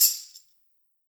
TambShake GarageX V10.wav